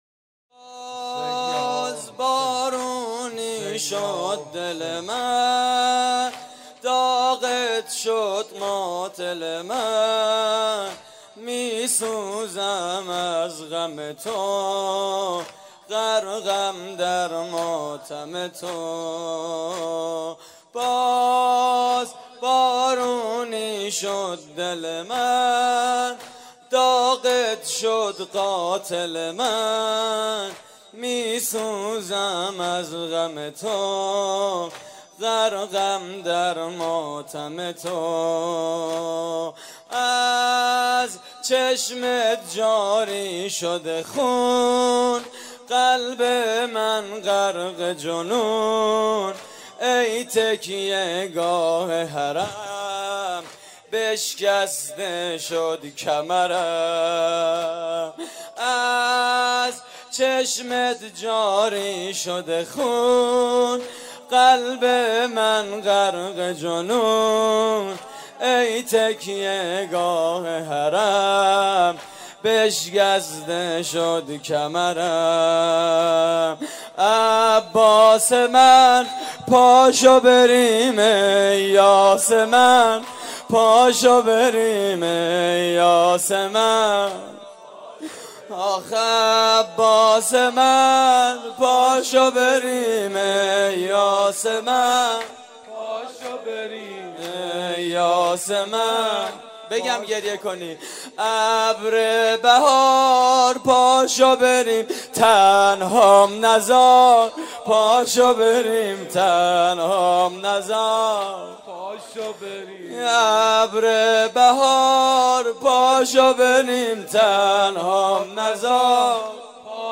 زمینه: داغت شد قاتل من متاسفانه مرورگر شما، قابیلت پخش فایل های صوتی تصویری را در قالب HTML5 دارا نمی باشد.
مراسم عزاداری شب تاسوعای حسینی